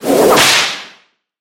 На этой странице собраны разнообразные звуки удара кнутом: от резких щелчков до протяжных свистов.
Щелчок кнута в воздухе